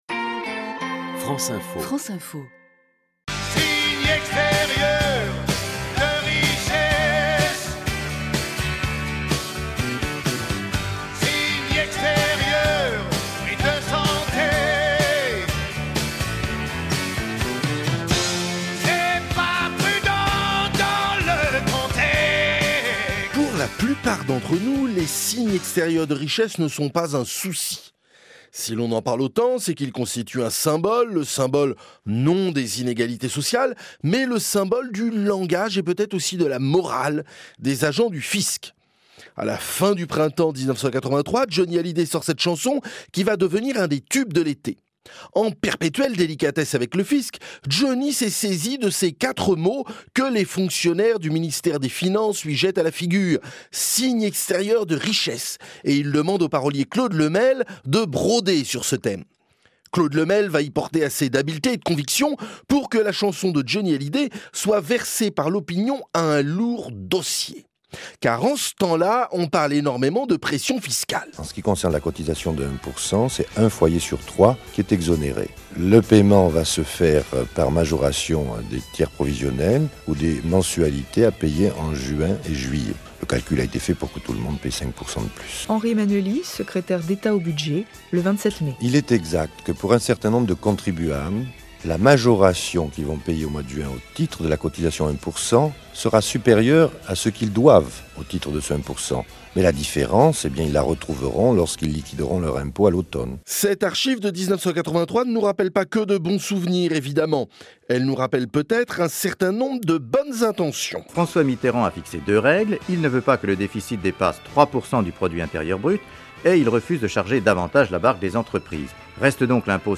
diffusées sur France Info
France Inter Inter actualités de 19H00 : Henri Emmanuelli, secrétaire d’Etat au budget, explique le fonctionnement du 1% 1983
France Inter Inter actualités de 13H00 : annonce d’augmentation des impôts 1983